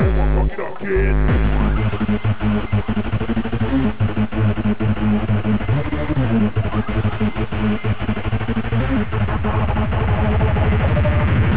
techno sample 1